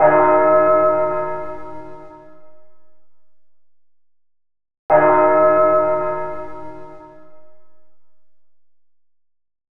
Hands Up - Church Bell.wav